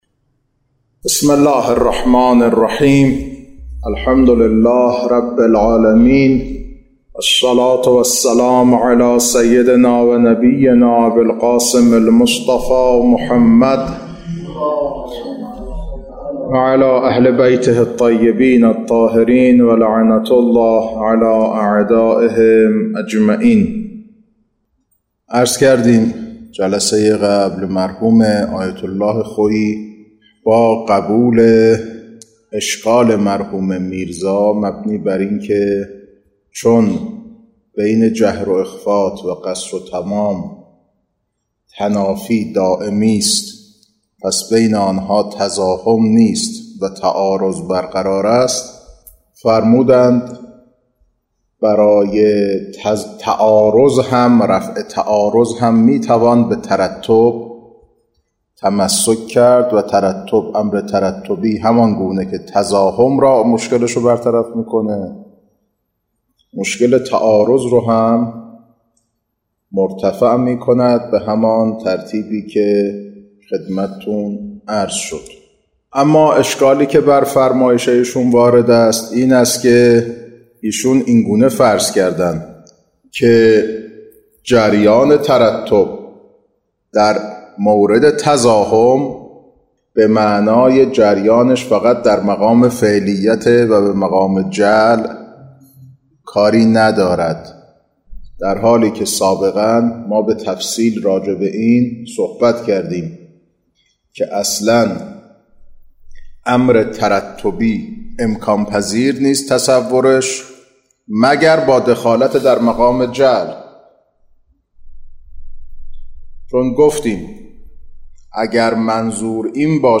خارج اصول، مبحث الفاظ